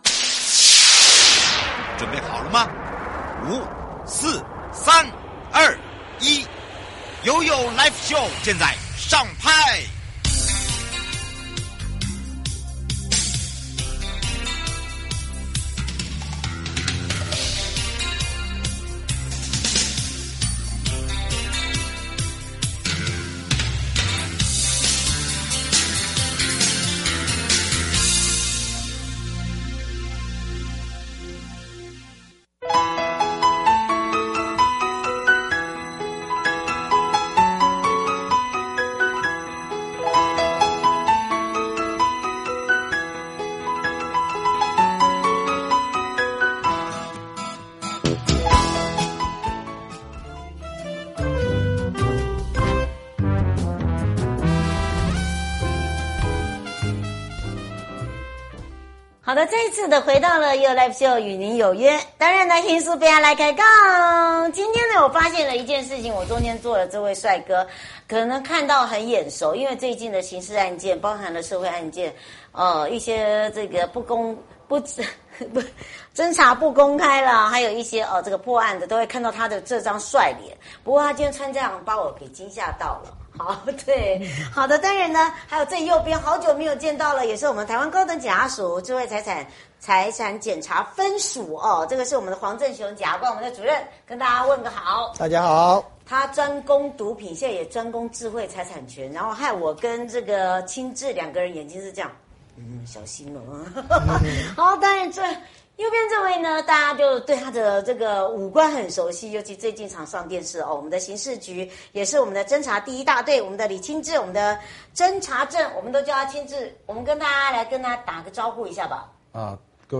受訪者： 直播 1.